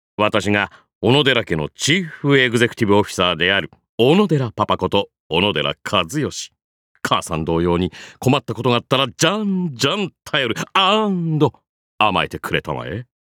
cha14kazuyoshi_voice_sample.mp3